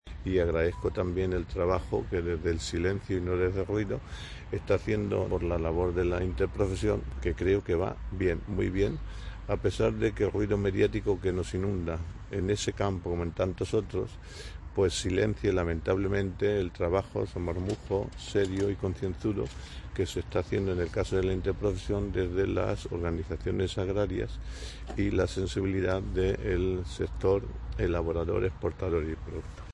• Durante el primer foro Conversa 'Ganadería: Investigación y futuro en Castilla-La Mancha', organizado por Cadena Ser, que ha tenido lugar en Centro de Investigación Ganadera (CERSYRA) de Valdepeñas.
cortealcalde.mp3